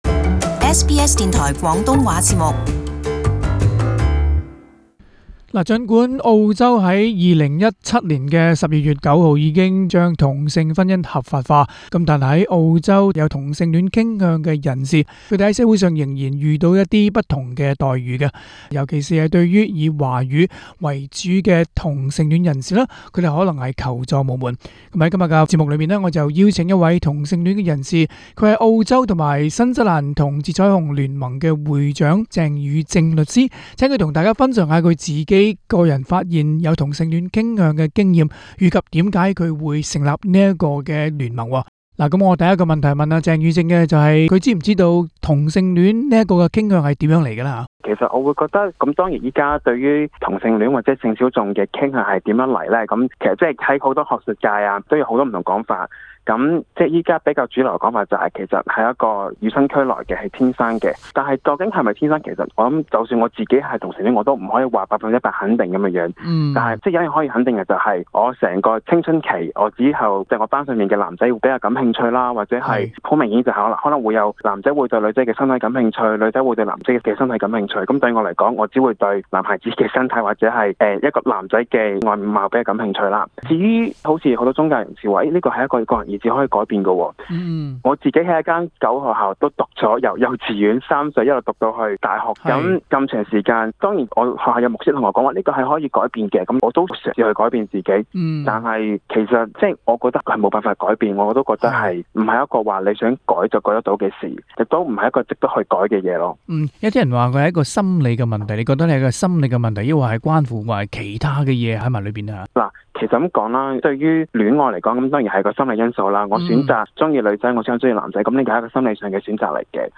【社區專訪】性小眾出柜容易嗎？